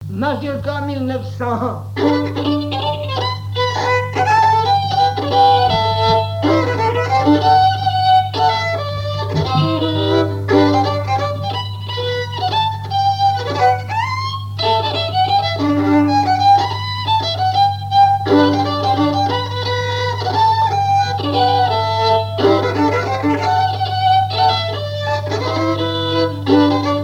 Mémoires et Patrimoines vivants - RaddO est une base de données d'archives iconographiques et sonores.
danse : mazurka
violoneux
Pièce musicale inédite